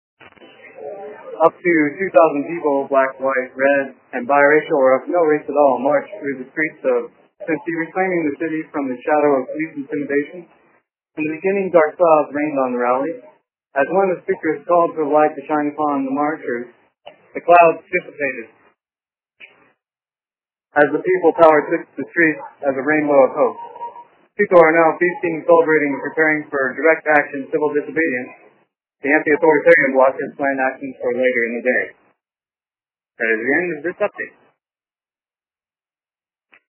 An audio summary of the 2000-strong march held in Cinci to oppose police violence. Following this march is the anti-authoritarian bloc and other direct actions.